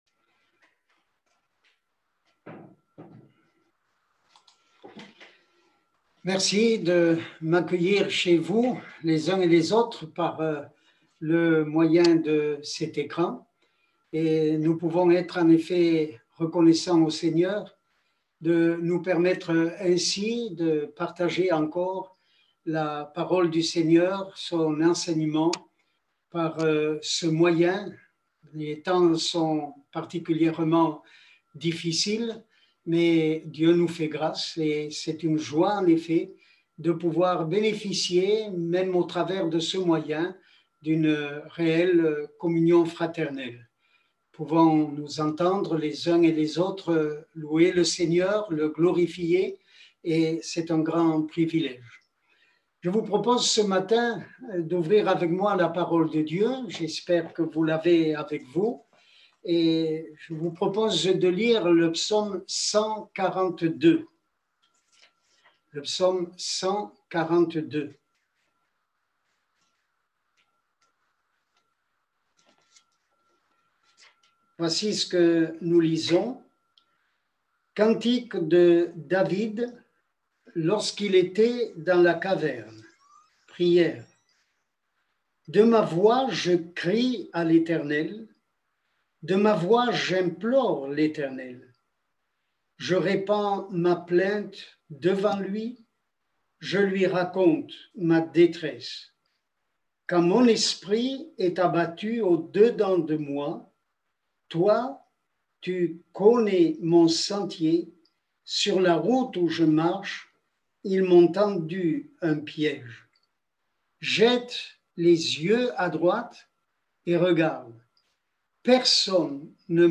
22 novembre 2020 La foi et l’obéissance Prédicateur